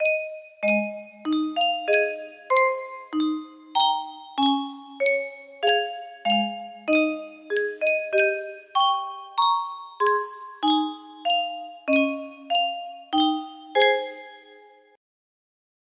Yunsheng Music Box - The Rose of Tralee Y849 music box melody
Full range 60